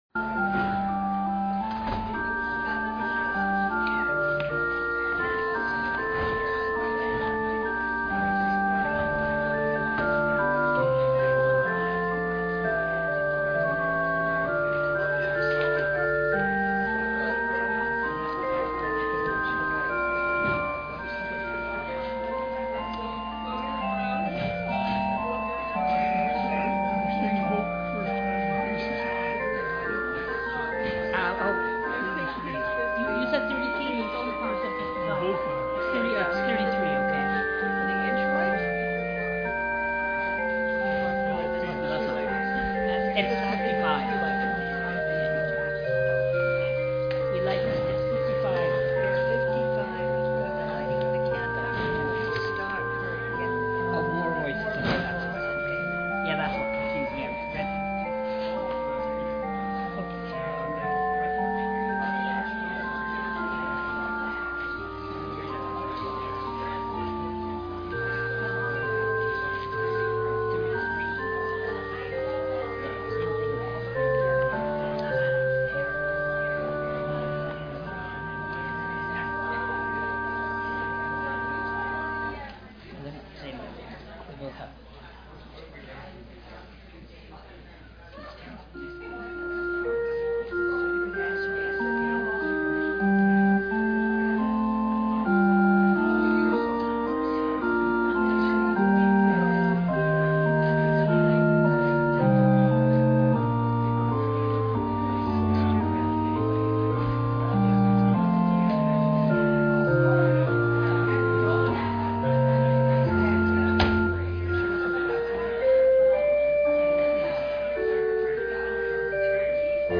Passage: Isaiah 43:18-21, Matthew 11:1-11 Service Type: Communion